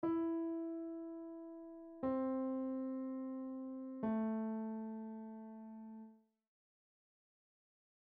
Piano Notes